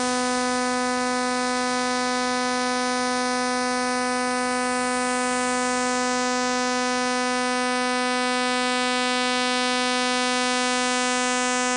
Unid_Radar_250Hz.mp3